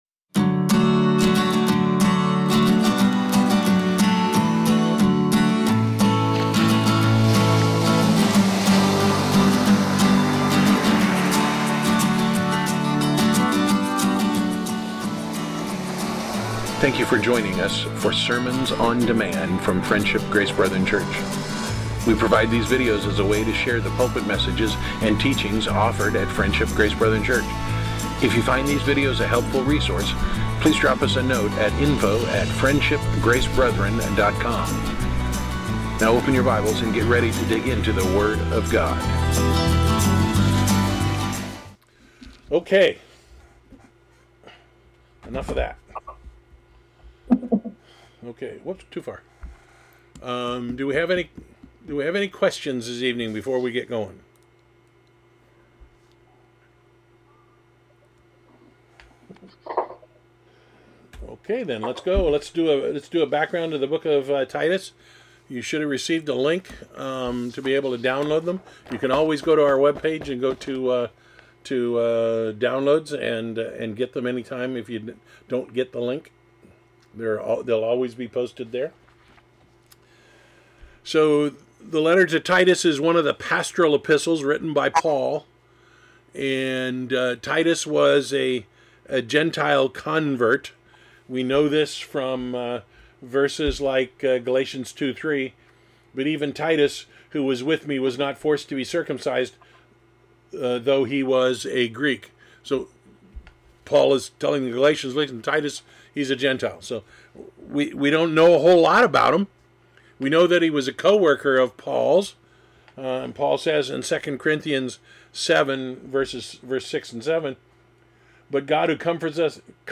Topic: Weekly Bible Reading Discussion